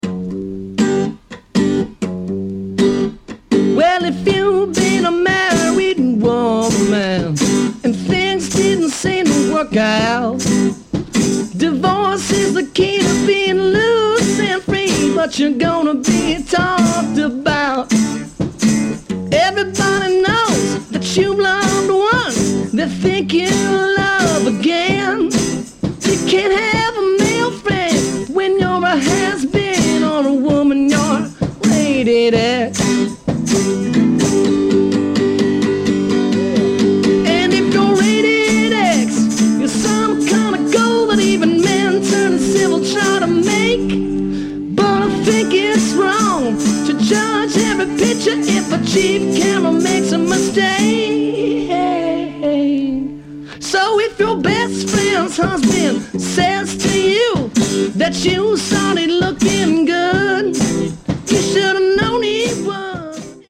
Pop, Rock, Psyche, Folk